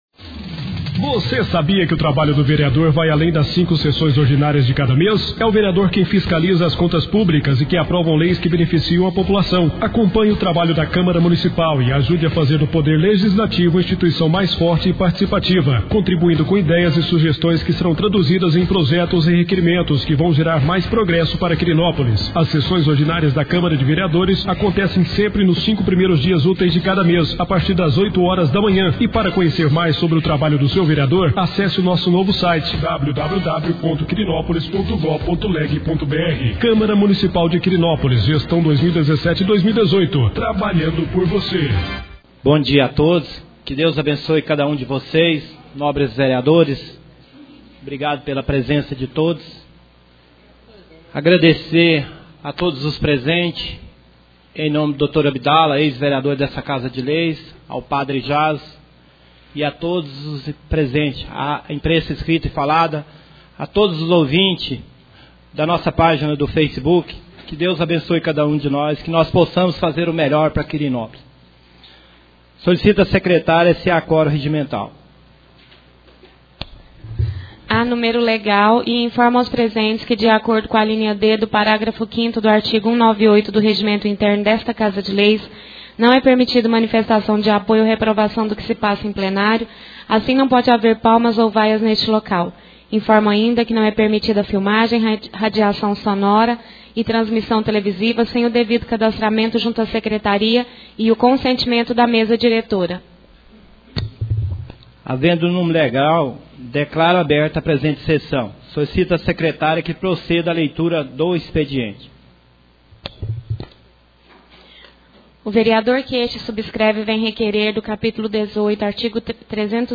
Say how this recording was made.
5ª Sessão ordinária do mês de Maio 2017